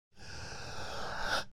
last-breath-sound